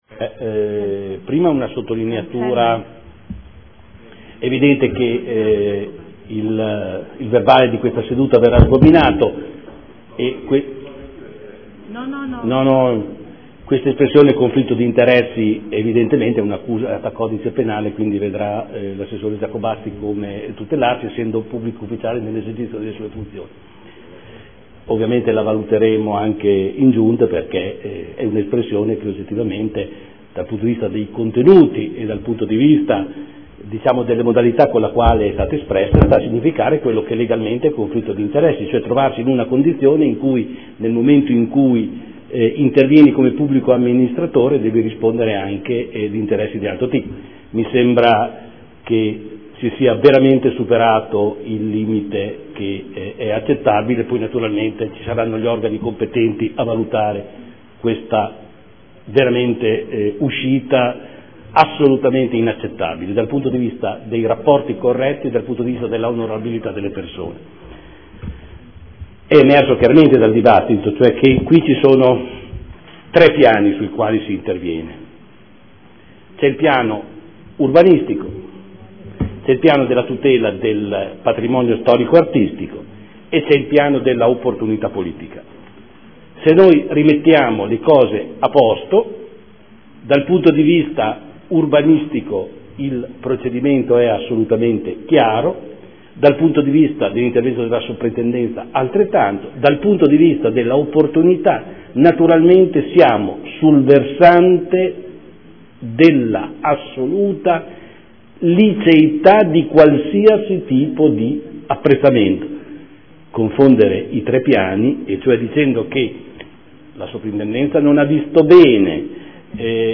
Seduta del 20/03/2014 Dibattito su interrogazioni 11 12 17